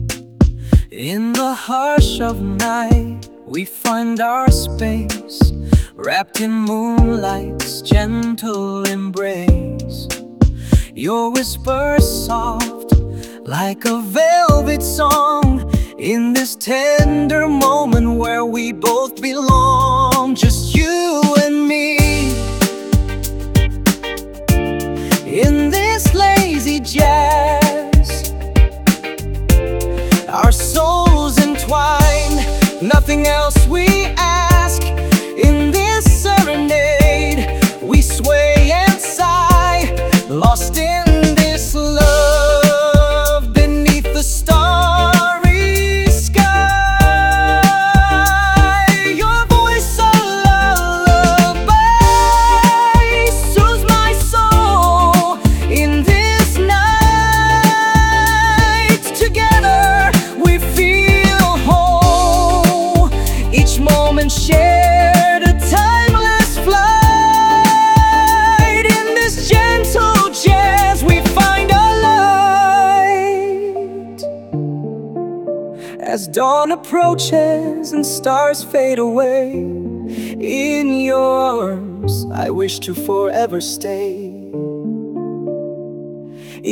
Music-1.5: Full-length songs (up to 4 mins) with natural vocals & rich instrumentation